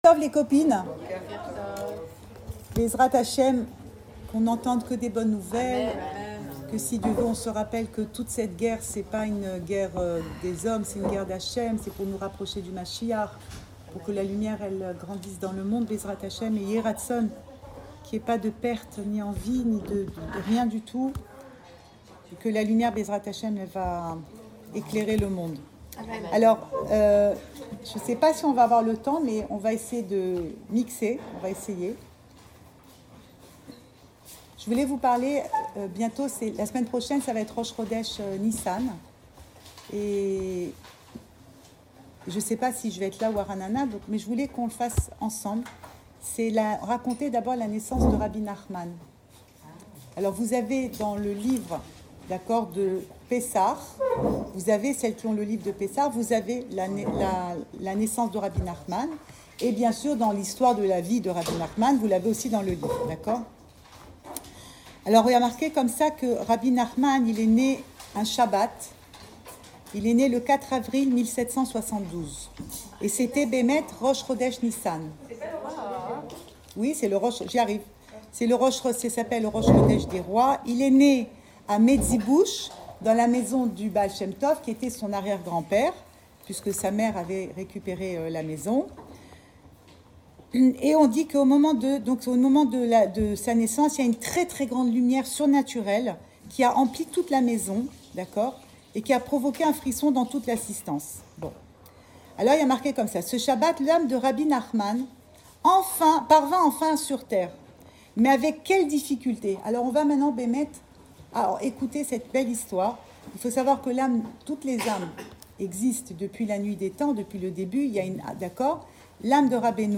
Cours audio Le coin des femmes Le fil de l'info Pensée Breslev - 11 mars 2026 11 mars 2026 La naissance de Rabbenou. Enregistré à Tel Aviv